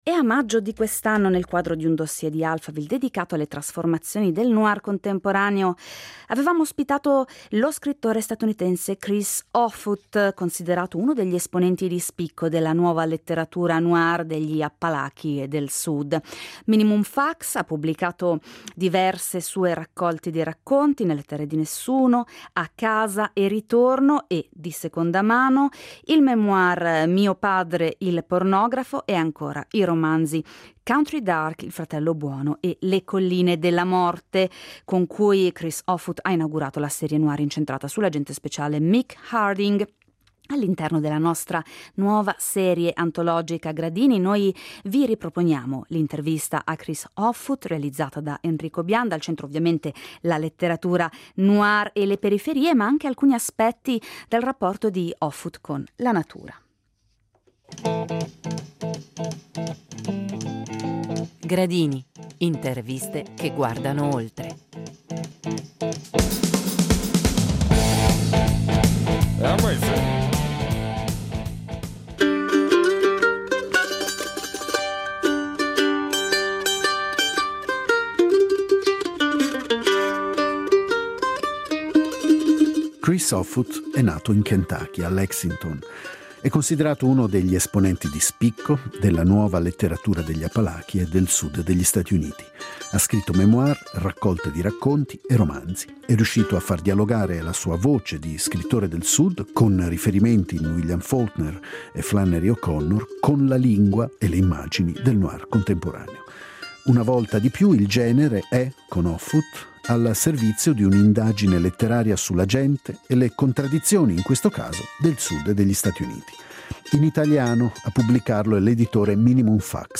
A maggio 2025, nel quadro di un Dossier di Alphaville dedicato alle trasformazioni del noir contemporaneo, abbiamo ospitato lo scrittore statunitense Chris Offutt, considerato uno degli esponenti di spicco della nuova letteratura degli Appalachi e del Sud.